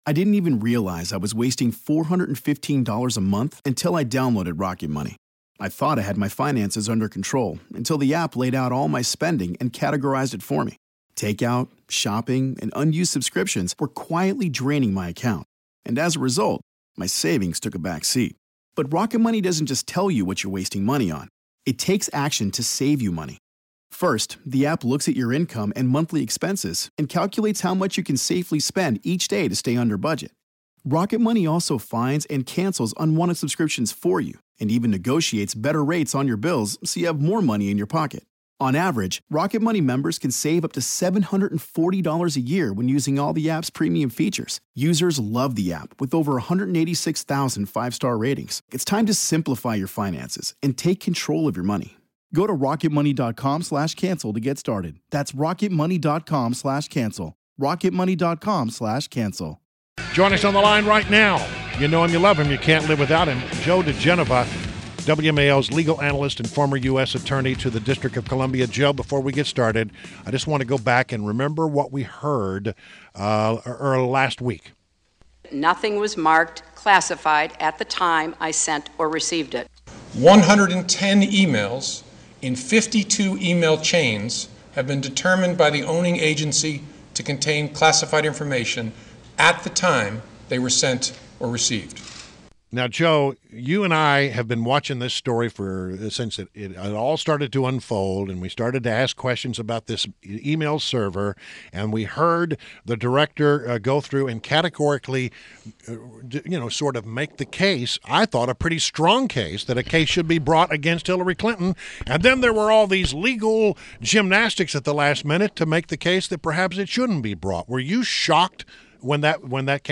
WMAl Interview - Joe Digenova - 07.11.16